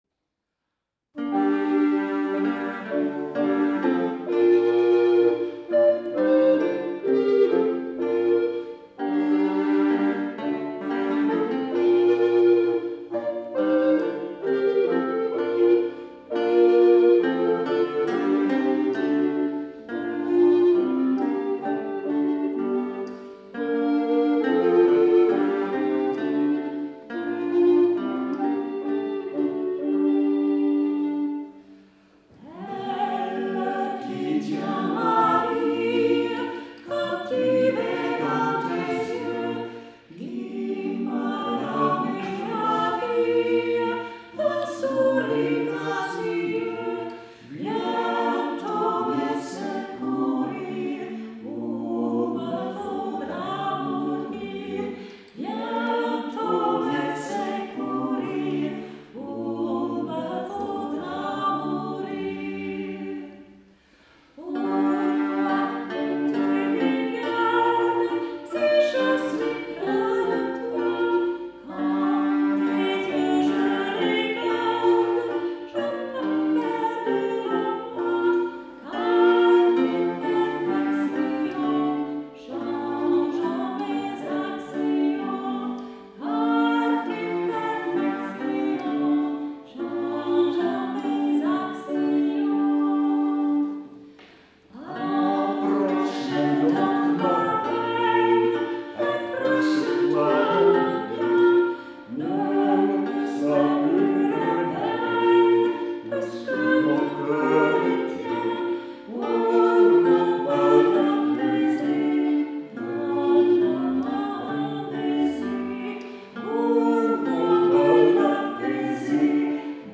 Die Stücke spiegeln höfische Liedkultur des 16. und 17. Jahrhunderts: